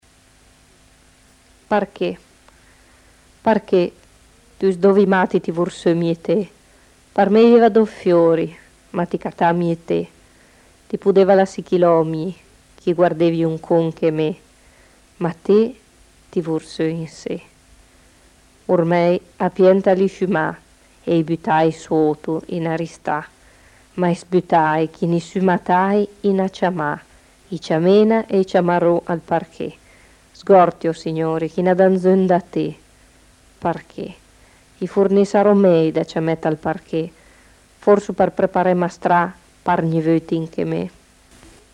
rima burlesca